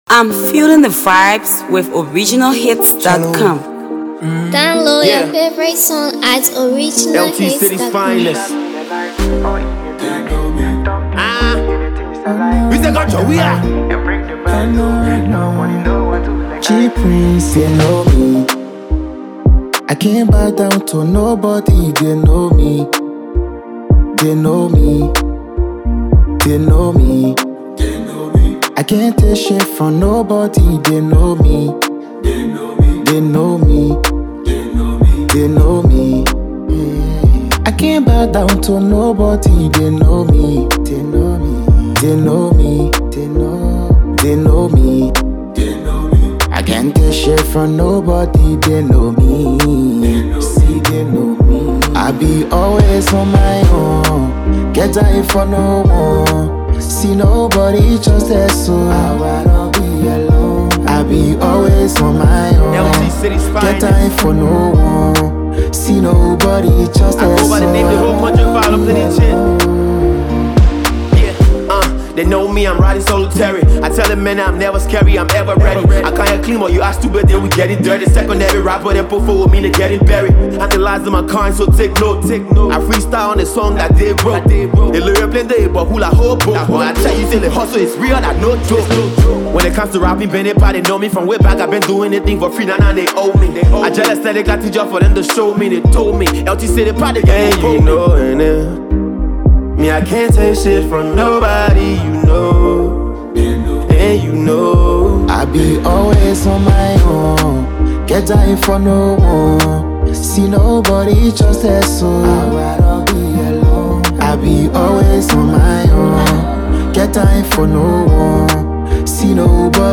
Hipco